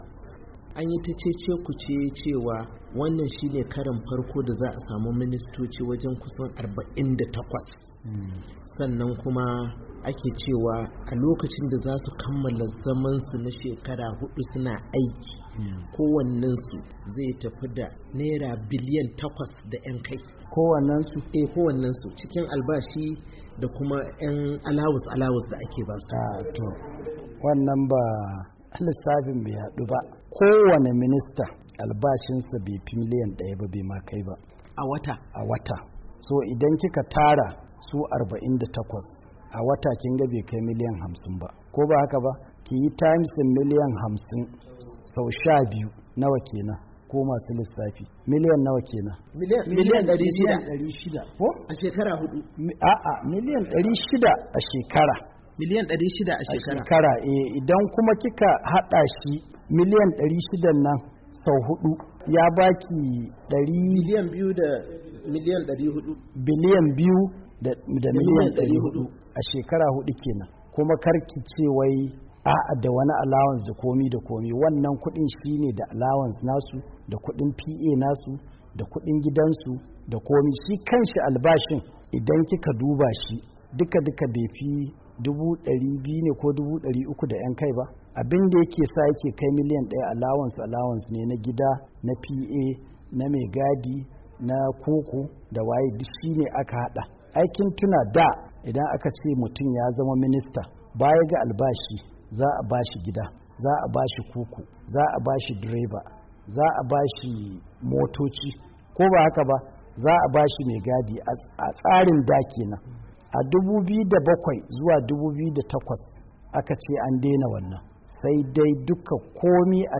Sai dai yayin wata hira da ya yi da Muryar Amurka, shugaban hukumar, Mohammed Bello Shehu, ya musanta rahotonnin da wasu kafafen yada labarai suka buga kan wannan batu.